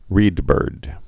(rēdbûrd)